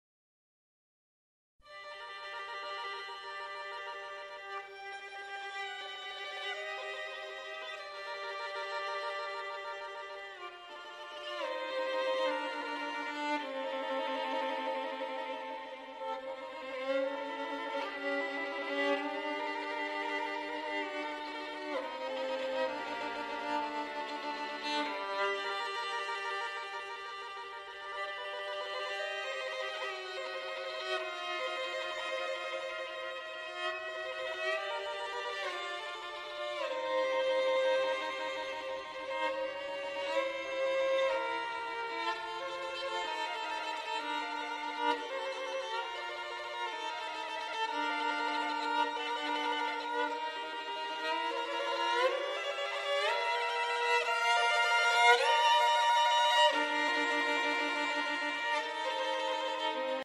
Niccolo Paganini - Capriccio 6 - Lento + midi file